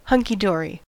Uttal
Alternativa stavningar hunkydory hunky dorey hunky-dorey hunkydorey hunky-dory Uttal US Okänd accent: IPA : /ˌhʌŋkiˈdɔːɹi/ Ordet hittades på dessa språk: engelska Ingen översättning hittades i den valda målspråket.